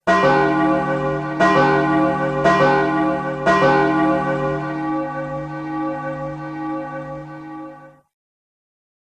Death Sound Effects MP3 Download Free - Quick Sounds